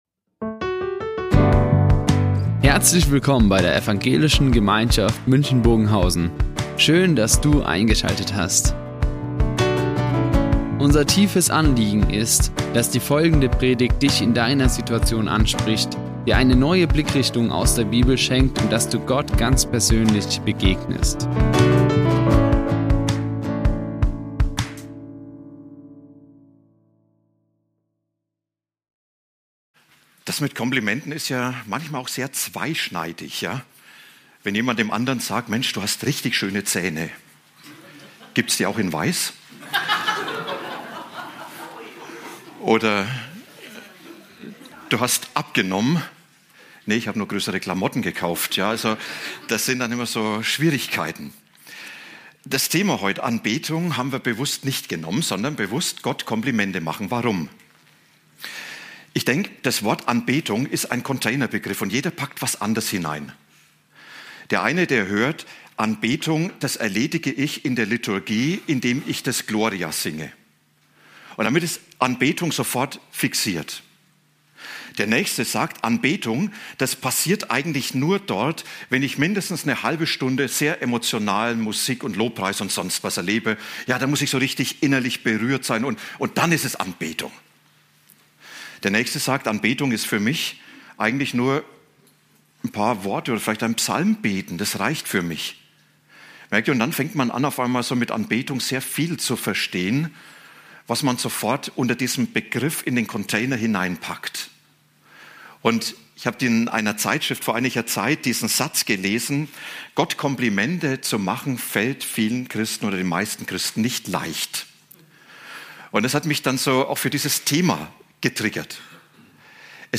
Beten heißt, Gott Komplimente zu machen | Predigt Lukas 1, 46-55 ~ Ev.
Gott Komplimente zu machen" Die Aufzeichnung erfolgte im Rahmen eines Livestreams.